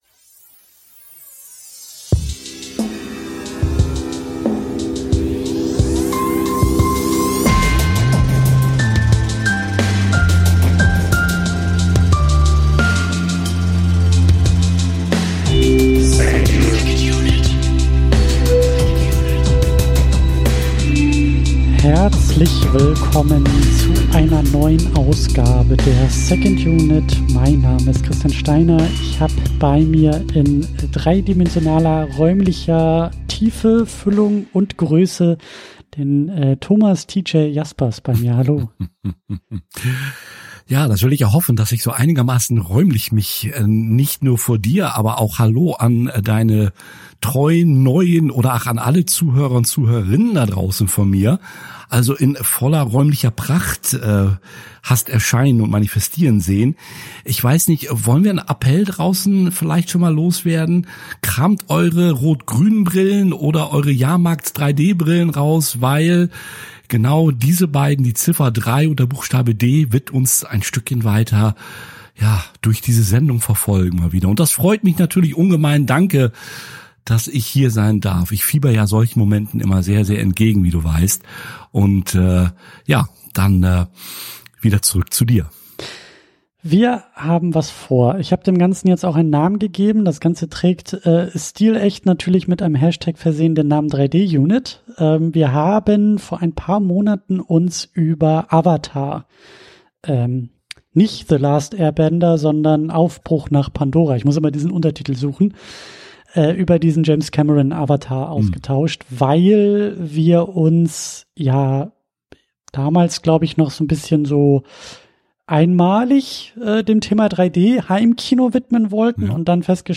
In einer Mischung aus philosophischem Gespräch und filmwissenschaftlichem Seminar entsteht dabei ein ganz eigenes Filmgespräch.